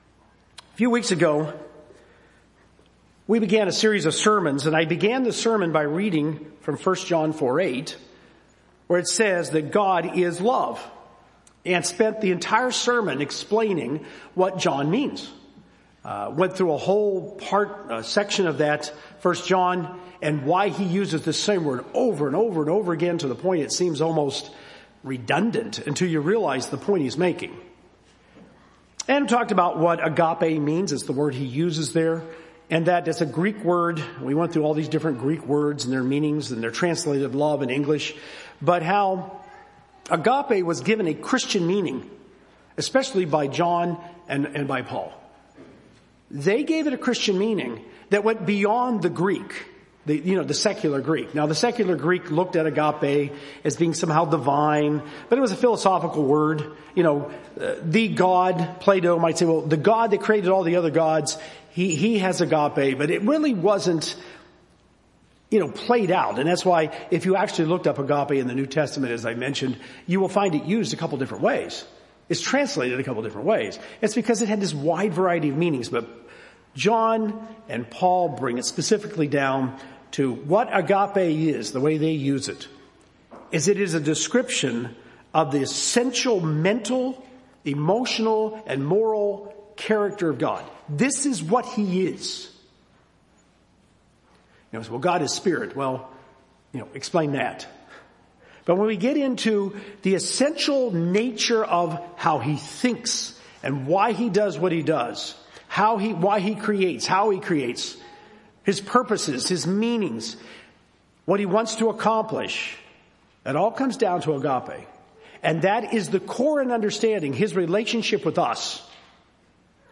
Romans 12 illuminates what kindness is. This sermon goes through these characteristics and expounds how they contribute to the agape love we are developing in our Christian walk.